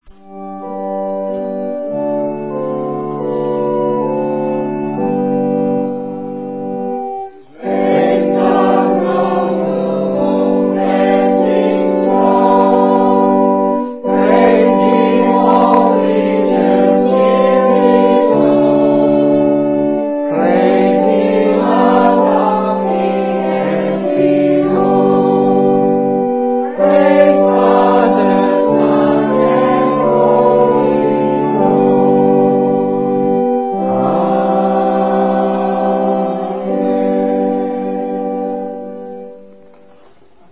Special performances
btn7 SP-1104-4 Praise God From Whom All Blessings Flow Hymn 694 - Hamilton Congregation